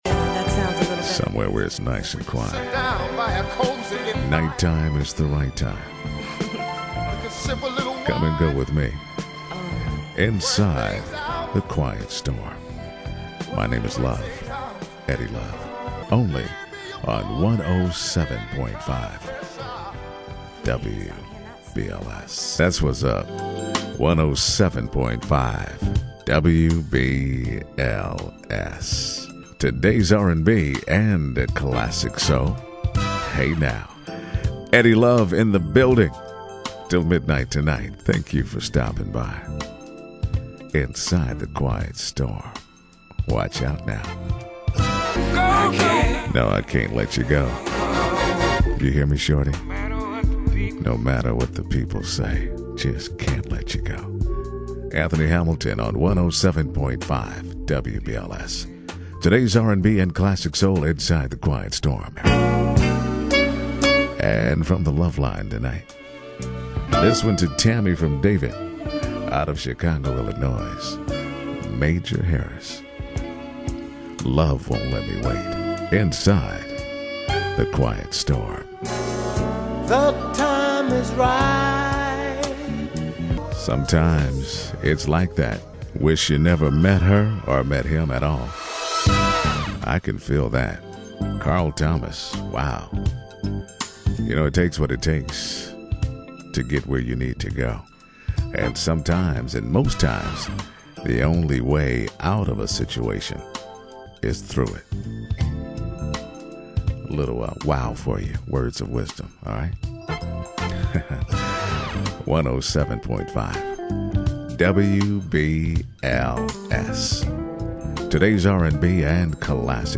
radio_wbls-the-quiet-storm.mp3